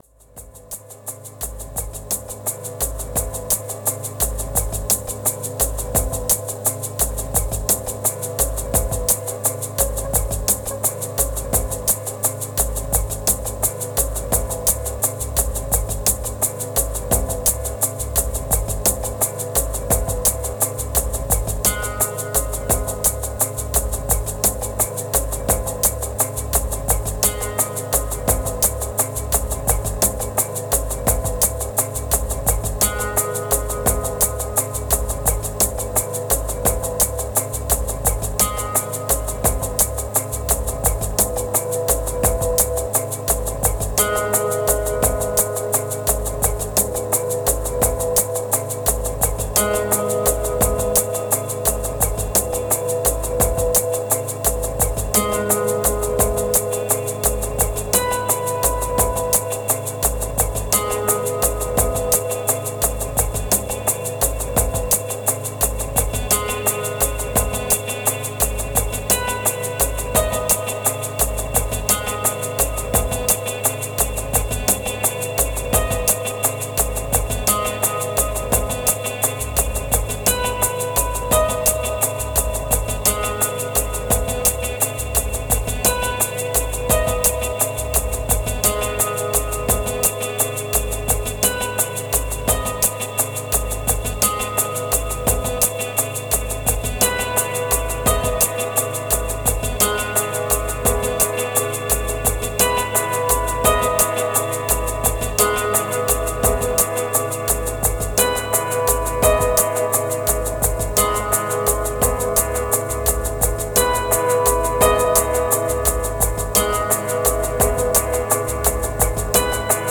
584📈 - 68%🤔 - 43BPM🔊 - 2021-07-02📅 - 349🌟